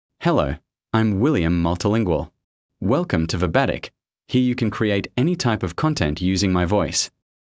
MaleEnglish (Australia)
William MultilingualMale English AI voice
William Multilingual is a male AI voice for English (Australia).
Voice: William MultilingualGender: MaleLanguage: English (Australia)ID: william-multilingual-en-au
Voice sample
Listen to William Multilingual's male English voice.